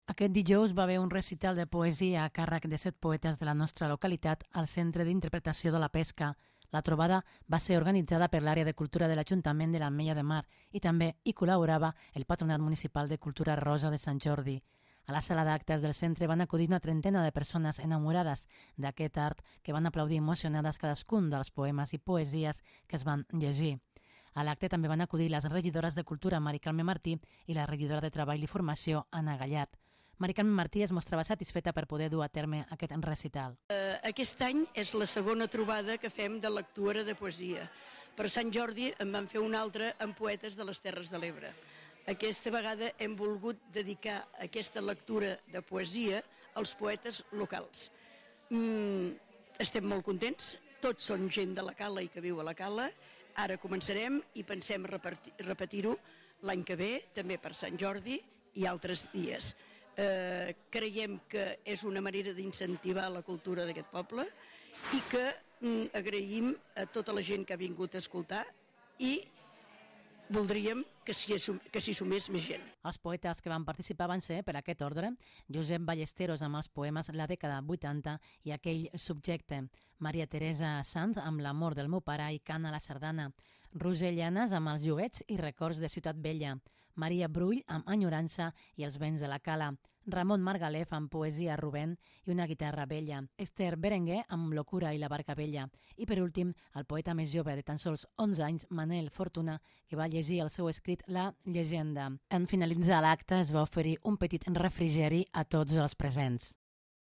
Els poetes locals ofereixen un recital al Centre d'Interpretació de la Pesca